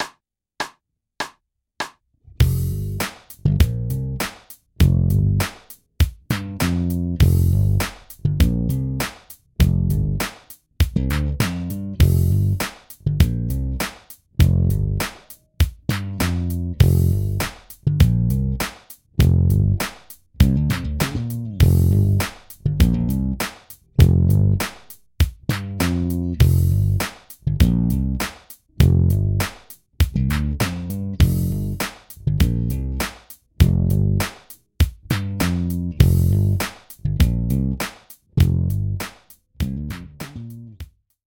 Groove Construction 4 Bass Groove Construction 4